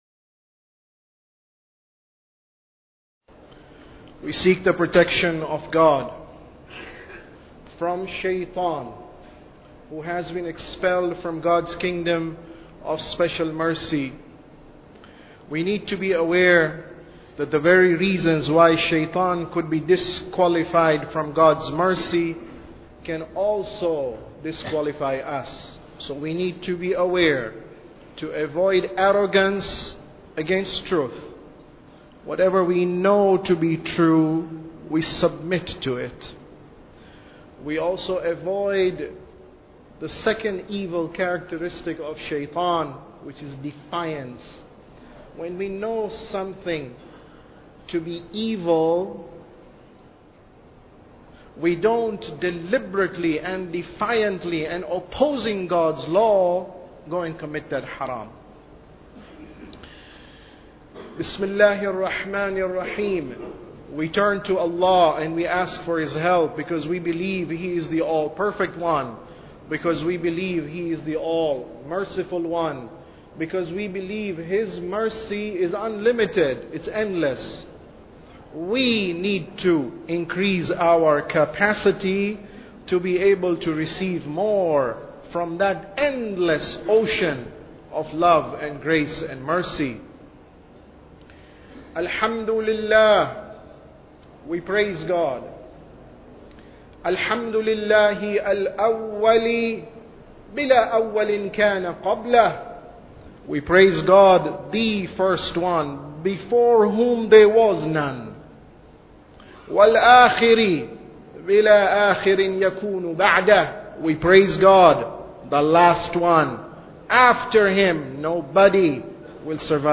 Sermon About Tawheed 1